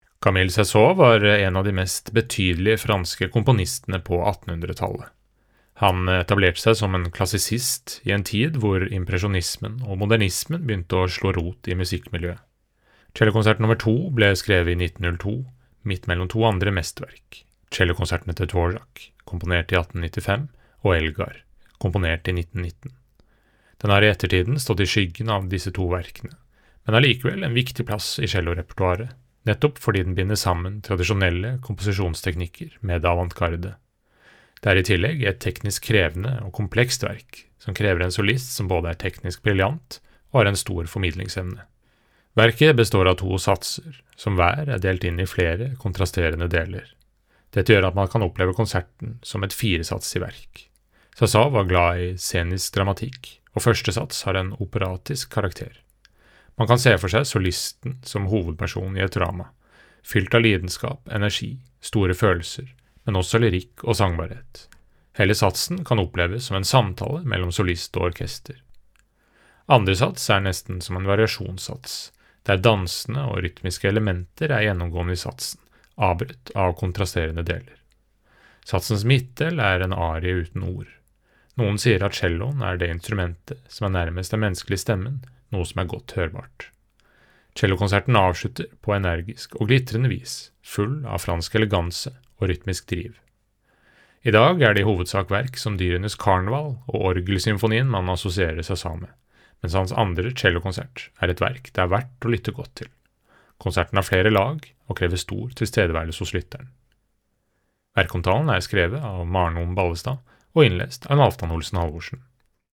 VERKOMTALE-Camille-Saint-Saens-Cellokonsert-nr.-2.mp3